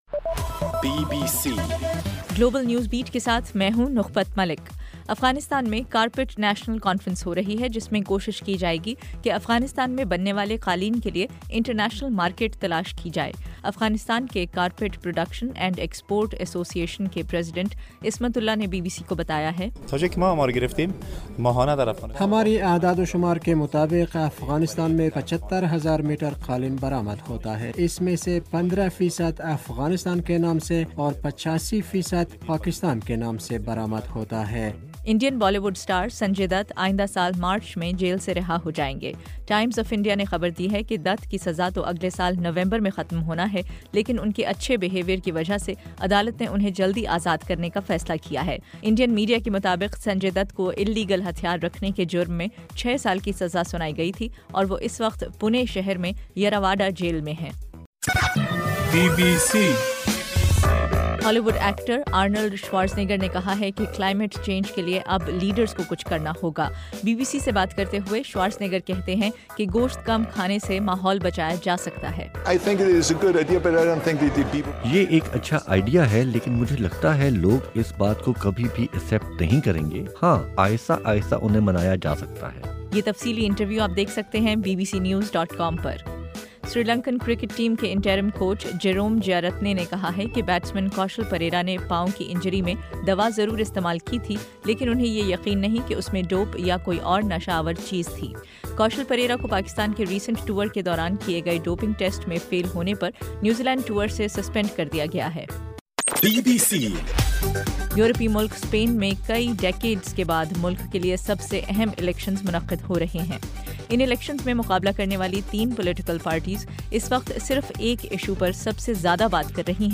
دسمبر 8: رات 12 بجے کا گلوبل نیوز بیٹ بُلیٹن